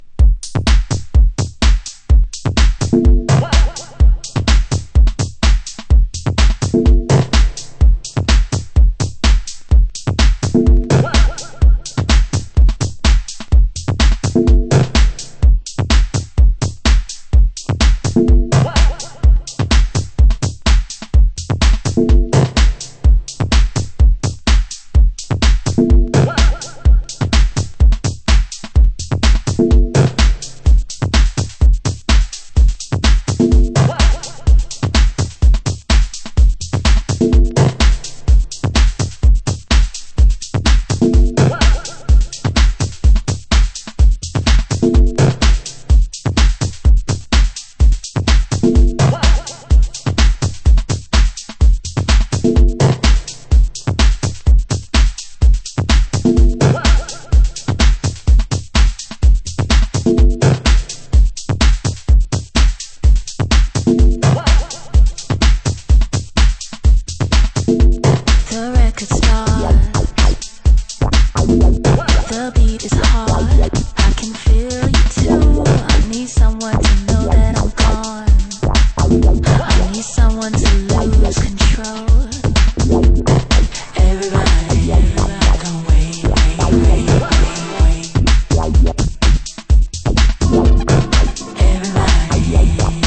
盤質：A1のイントロ部に１センチ程の 傷/チリパチノイズ有